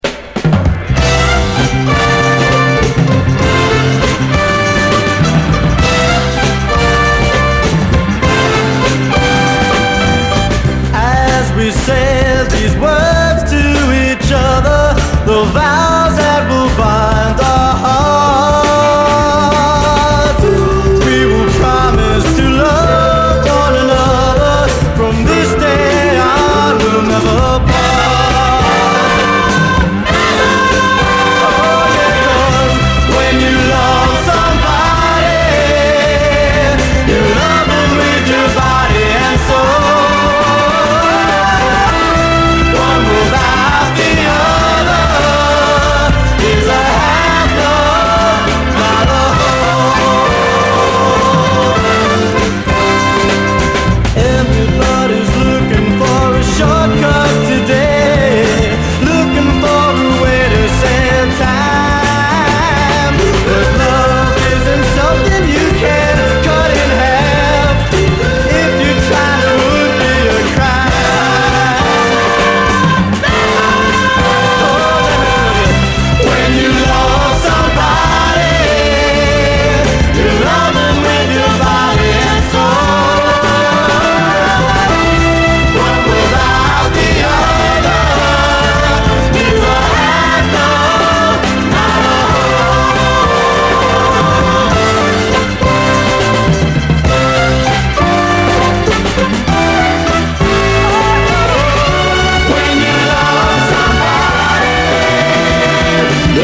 SOUL, 70's～ SOUL, 7INCH
青臭い青春フレーヴァー！